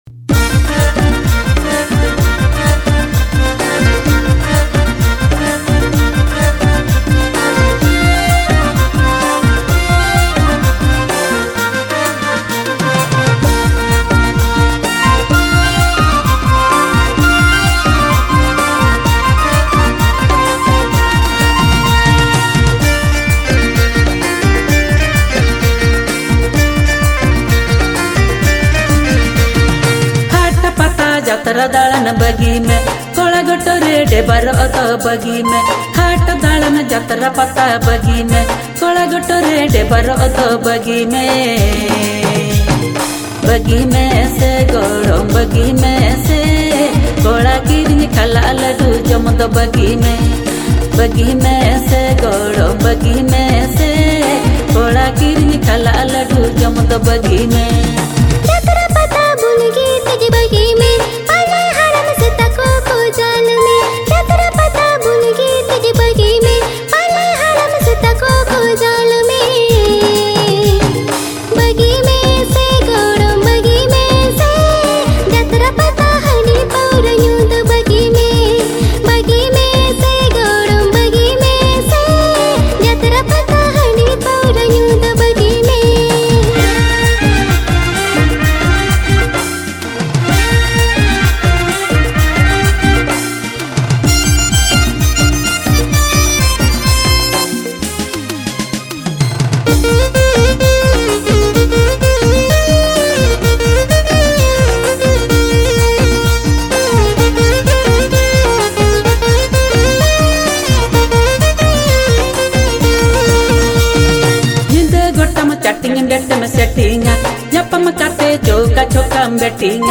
Santali song